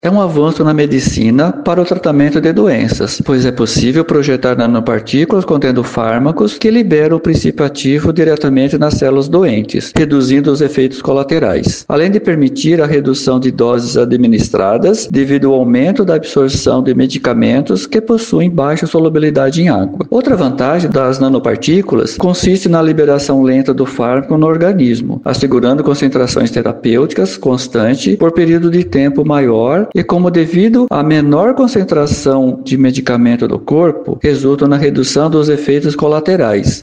Em entrevista à FM 104.7